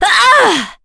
Epis-Vox_Attack2.wav